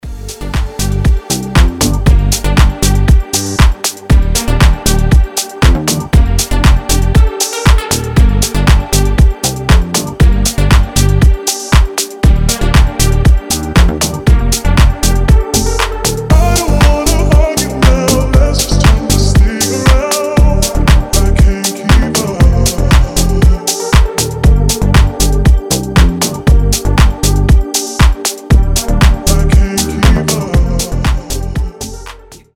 • Качество: 320, Stereo
deep house
медленные
Размеренная дип композиция